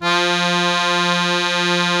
MUSETTE 1 DI.wav